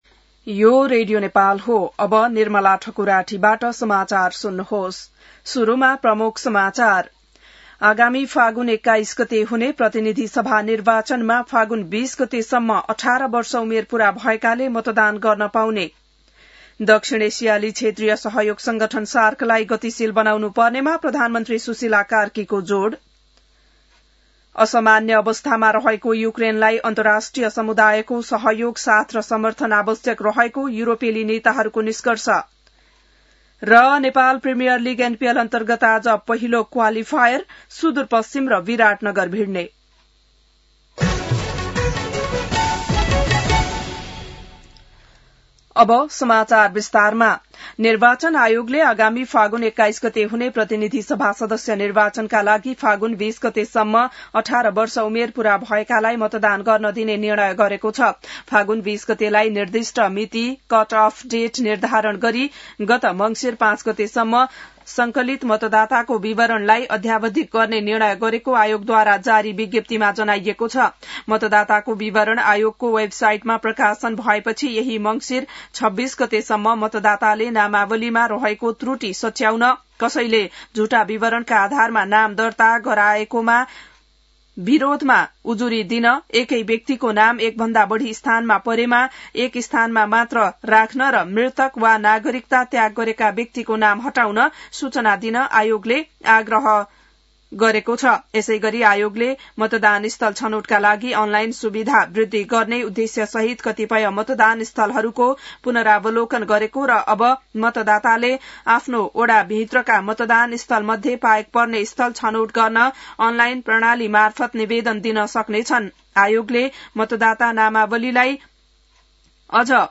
बिहान ९ बजेको नेपाली समाचार : २३ मंसिर , २०८२